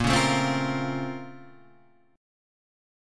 BbmM9 chord